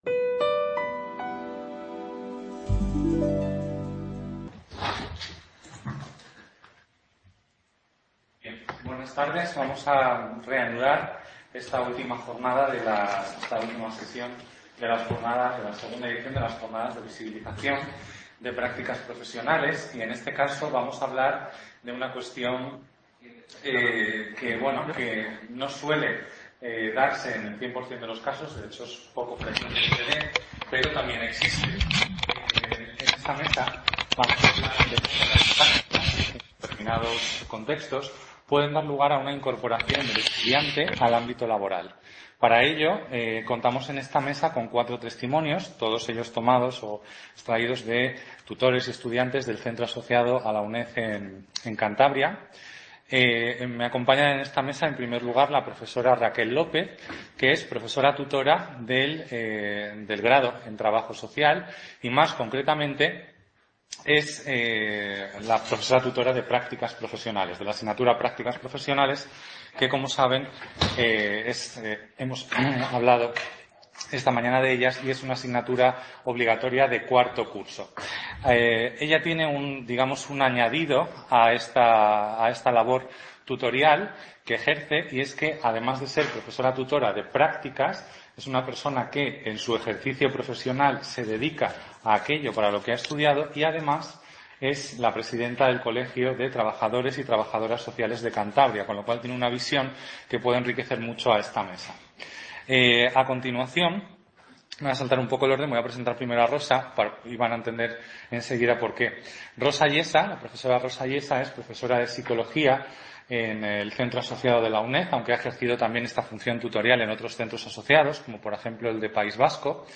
CA Cantabria - II Jornadas de Visibilización de las Prácticas Profesionales. La supervisión de las prácticas en el Centro Asociado: el papel del profesor tutor de la UNED.